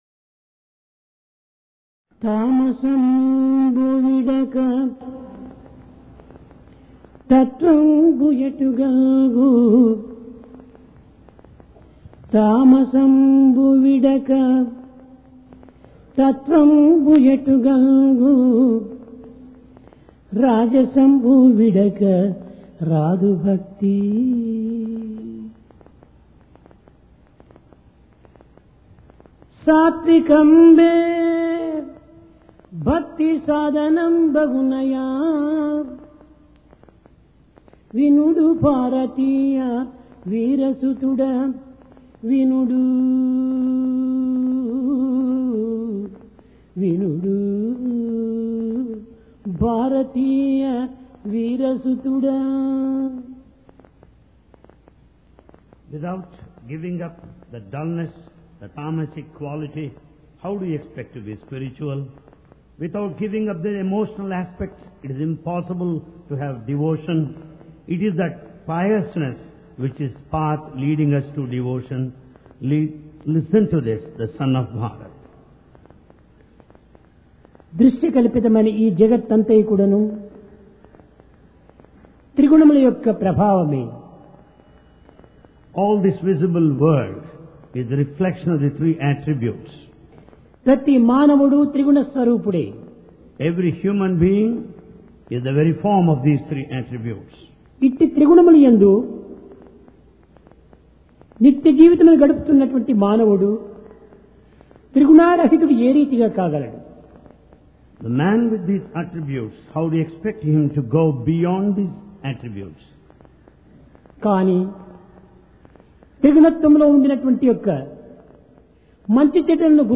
PRASHANTI VAHINI - DIVINE DISCOURSE 3 JULY, 1996
Sai Darshan Home Date: 3 Jul 1996 Occasion: Divine Discourse Place: Prashanti Nilayam Transcending The Three Gunas The visible world is the reflection of the three gunas (tamasic, rajasic, satwic) .